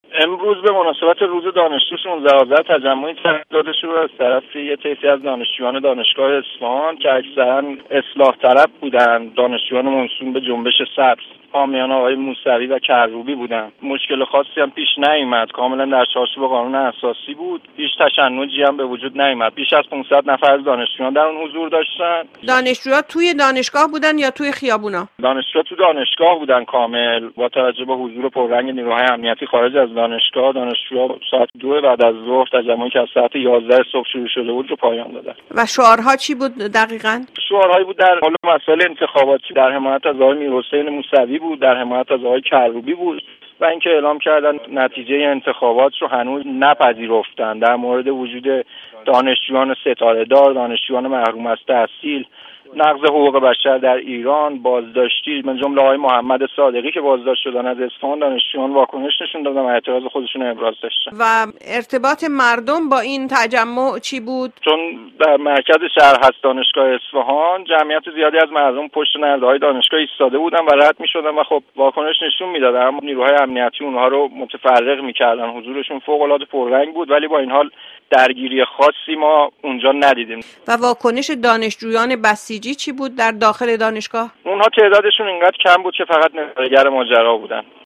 گزارشی از تجمع دانشجویان اصفهان توسط یک شاهد عینی:
ISPAHAN_-_Manifs_SON.mp3